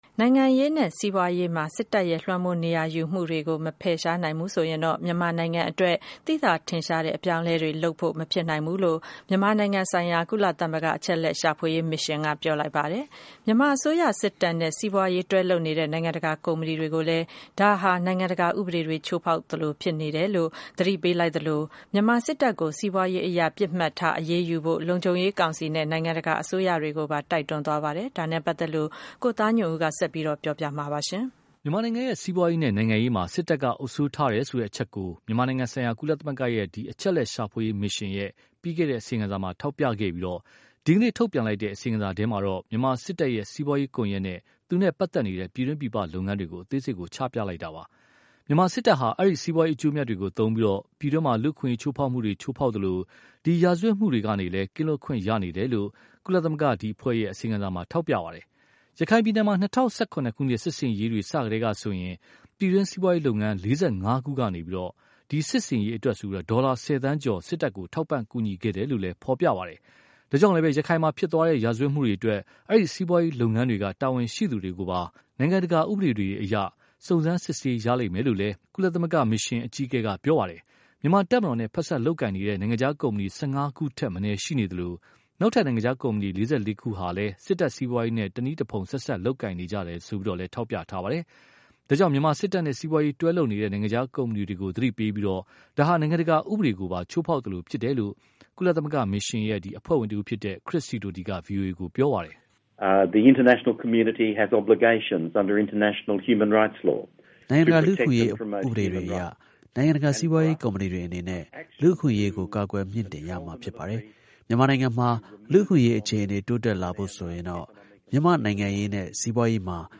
ကုလ အချက်အလက်ရှာဖွေရေးမစ်ရှင် သတင်းစာရှင်းလင်း